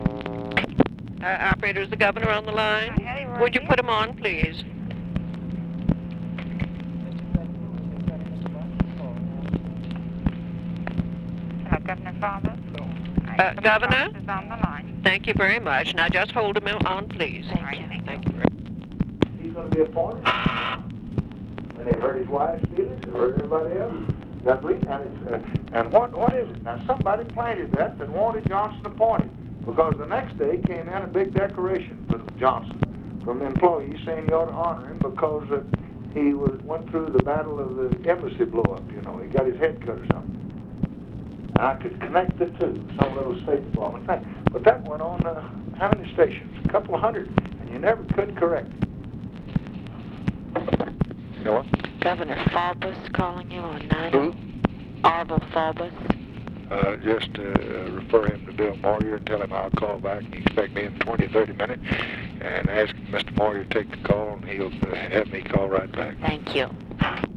Conversation with OFFICE SECRETARY and OFFICE CONVERSATION, April 26, 1965
Secret White House Tapes